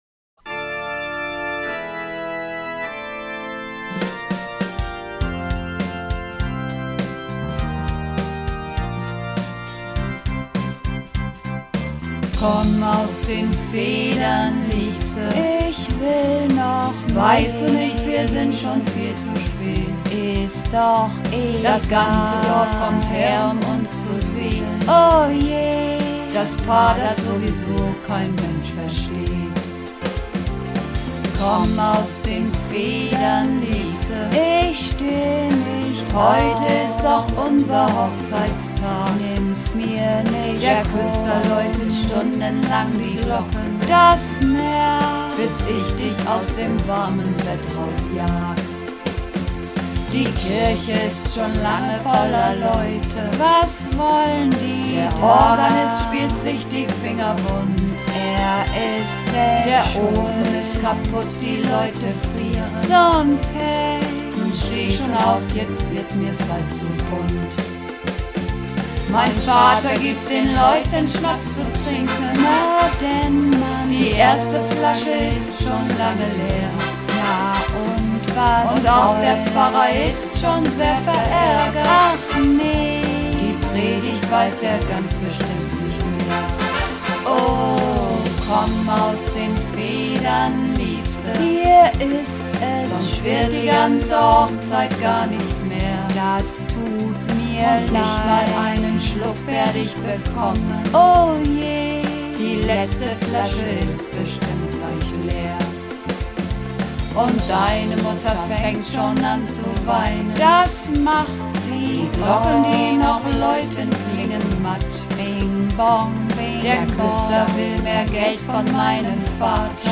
Übungsaufnahmen - Komm aus den Federn, Liebste!
Komm_aus_den_Federn_Liebste__4_Mehrstimmig.mp3